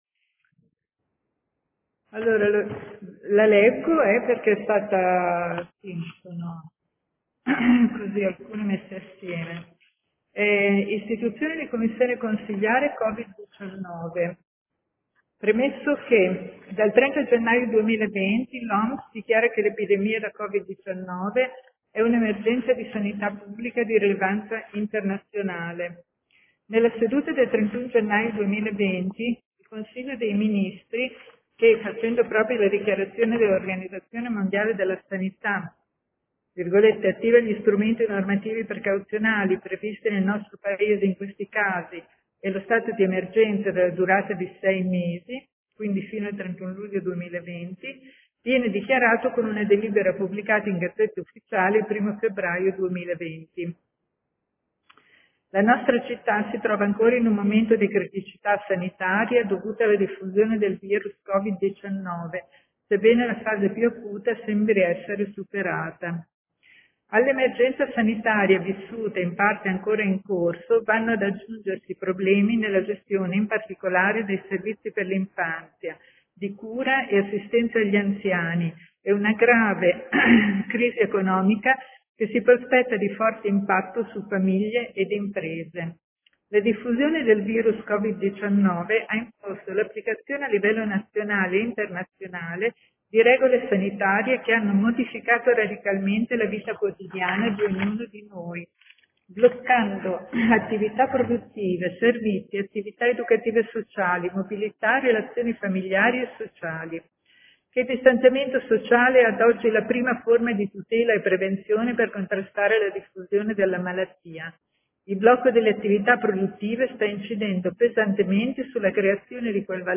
Paola Aime — Sito Audio Consiglio Comunale